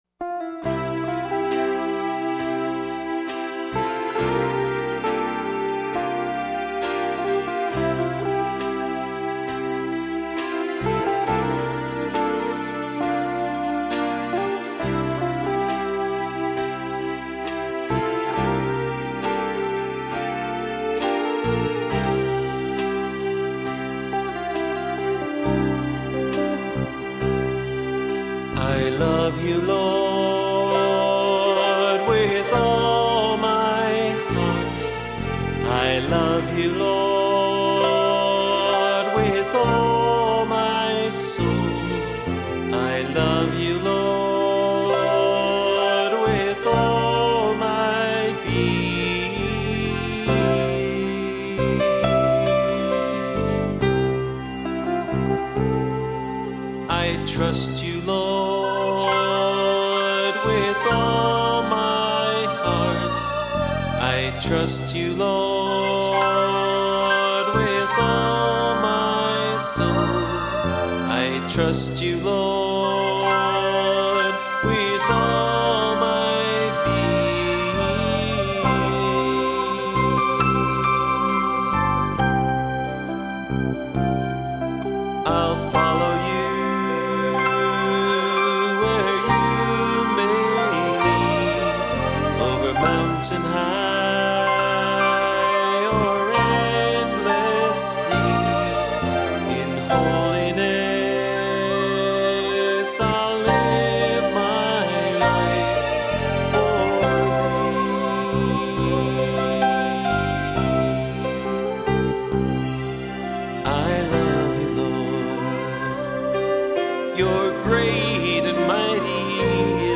This was the first album that we recorded in our own studio.